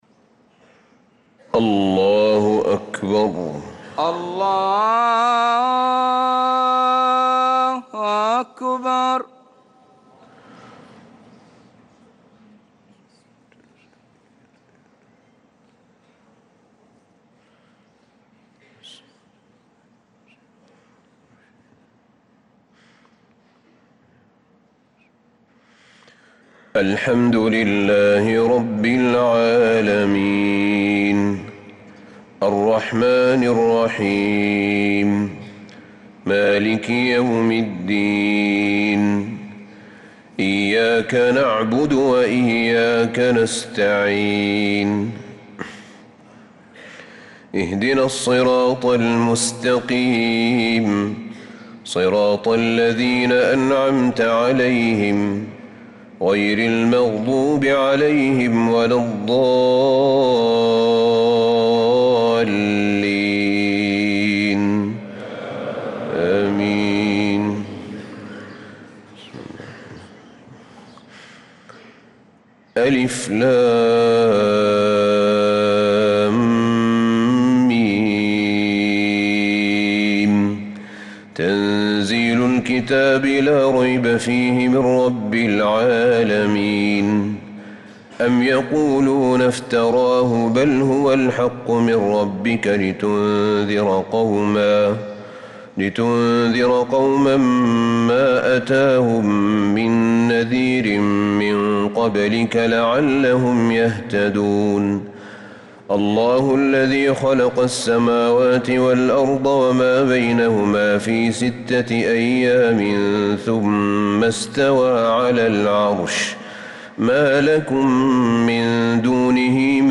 صلاة الفجر للقارئ أحمد بن طالب حميد 17 رجب 1446 هـ